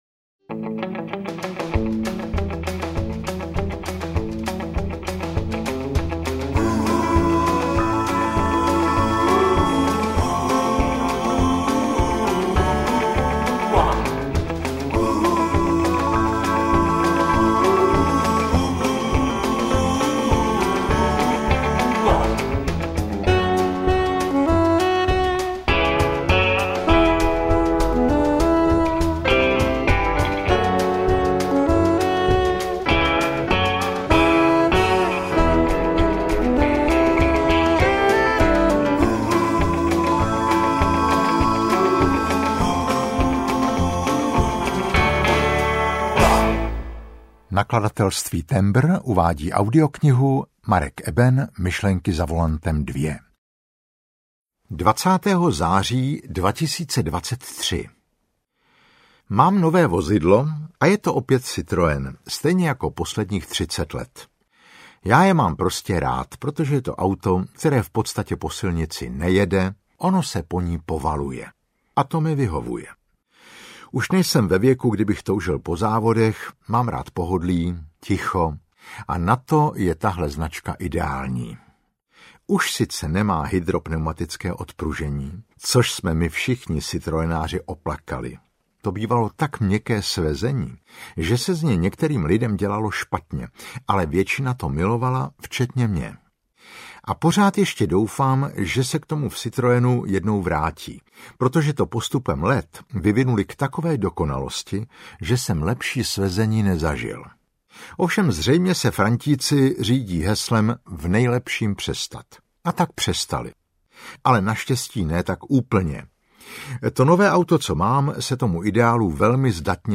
Audiobook
Read: Marek Eben